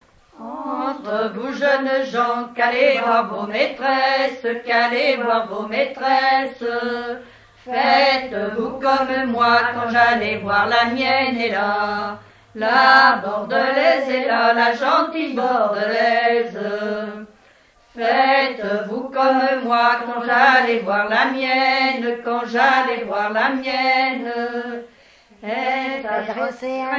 Témoignages sur la pêche, accordéon, et chansons traditionnelles